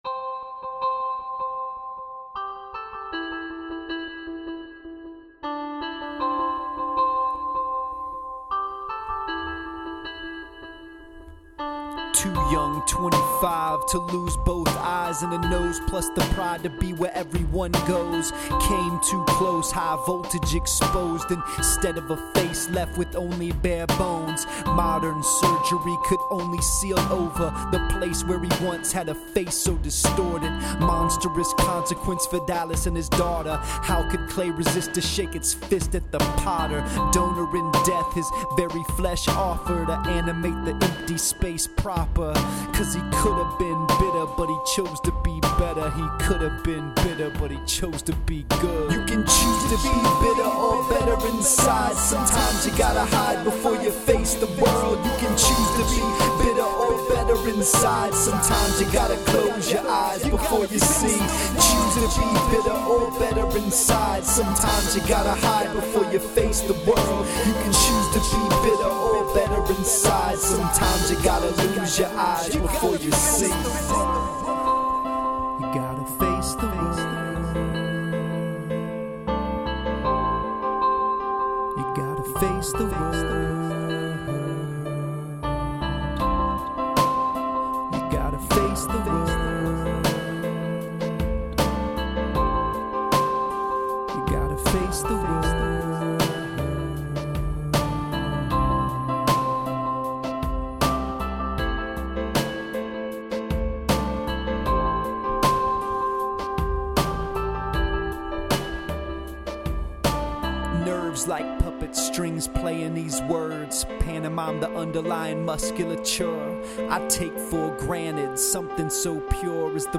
I had to shoo a raccoon from our cat’s food bowl before settling down to record at my daughters’ craft table.